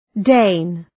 Shkrimi fonetik {deın}